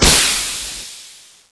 jump_sound.wav